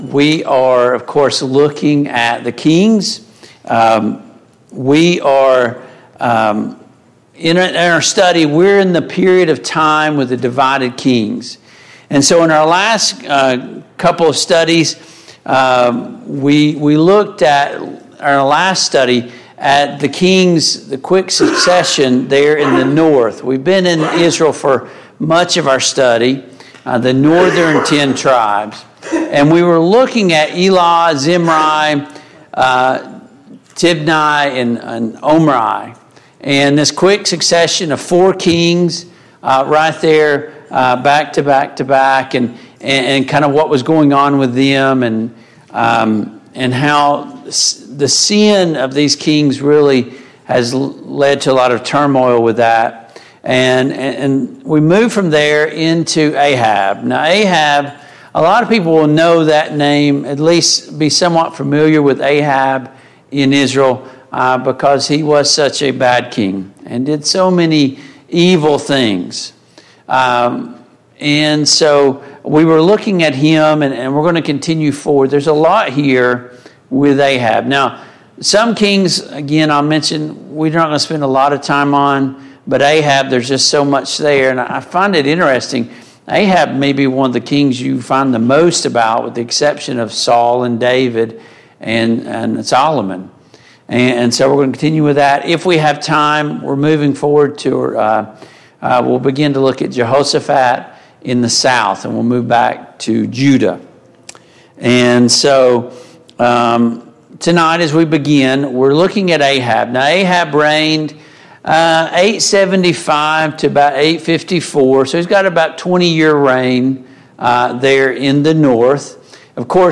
The Kings of Israel Passage: 1 Kings 16, 1 Kings 17, 1 Kings 18 Service Type: Mid-Week Bible Study Download Files Notes Topics